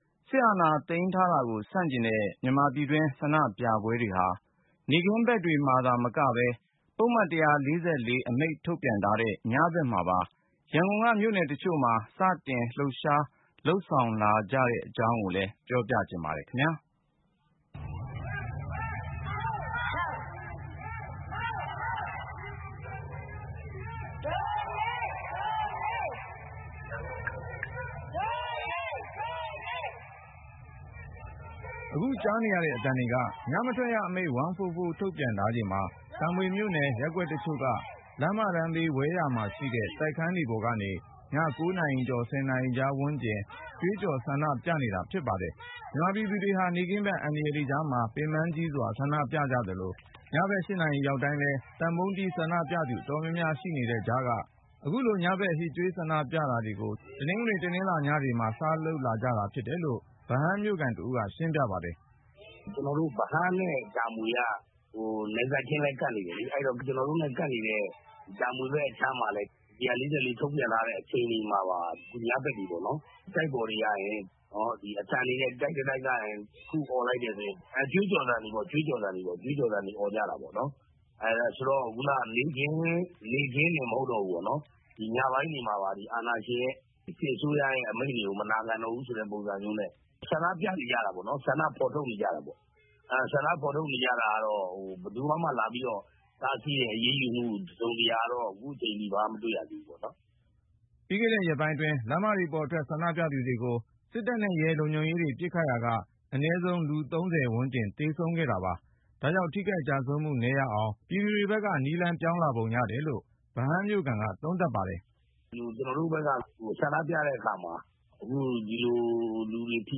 အခုကွားနရေတဲ့ အသံတှကေ ညမထှကျရအမိန့ျ 144 ထုတျထားခြိနျမှာ တာမှမွေို့နယျ ရပျကှကျတခြို့က လမျးမတနျးဘေး ဝဲယာမှာရှိတဲ့ တိုကျခနျးတှပေေါျကနေ ည ၉ နာရီကြောျ ၁၀ နာရီကွားဝနျးကငြျ ကွှေးကွောျ ဆန်ဒပွနကွေတာဖွဈပါတယျ။